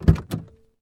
openDoor.wav